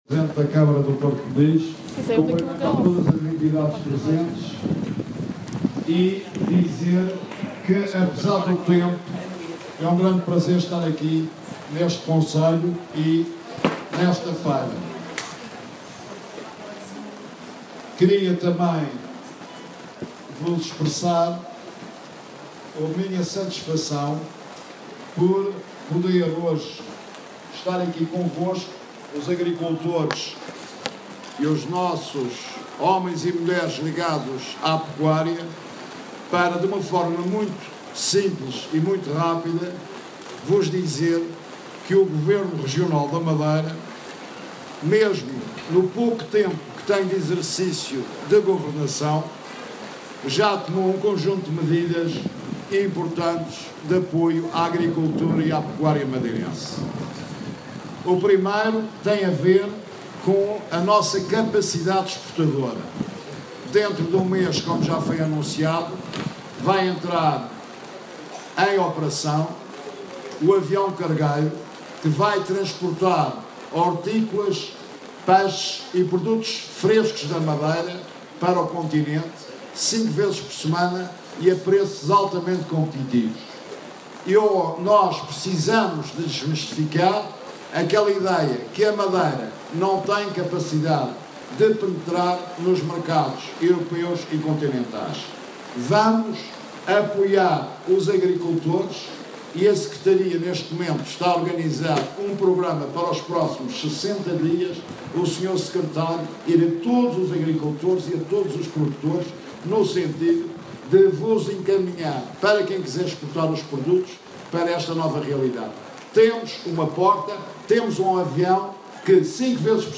(Ficheiro áudio com a Intervenção do Presidente do GR na 60ª Feira Agropecuária do Porto Moniz disponível em anexo.)